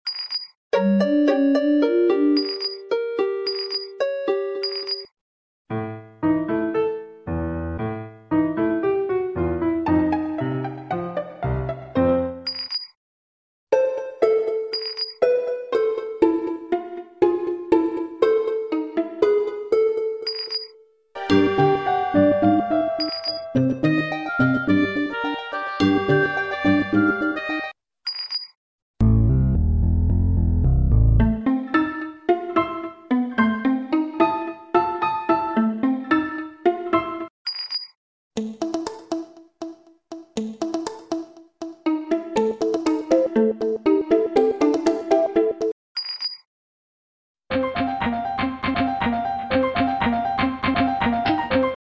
Plants vs. Zombies sound effects sound effects free download
Plants vs. Zombies sound effects collection.